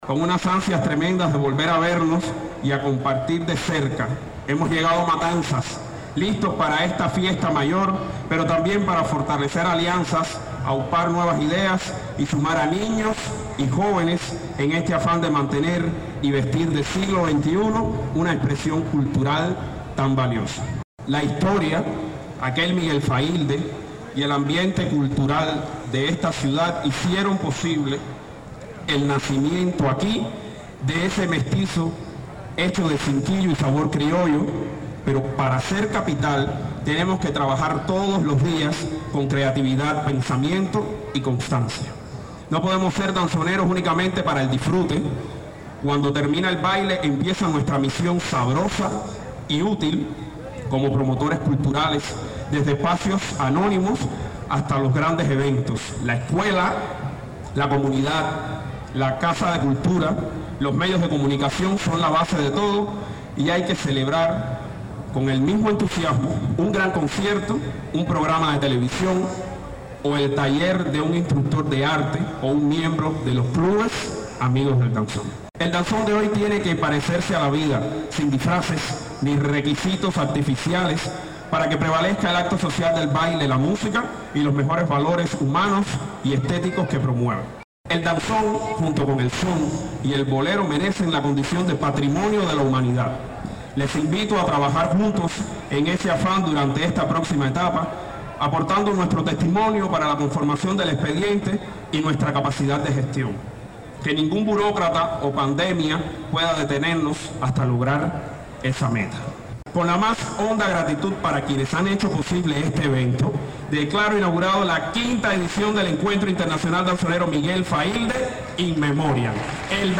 Fragmentos-de-palabras-inaugurales-.mp3